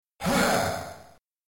splatter_masterlaugh.mp3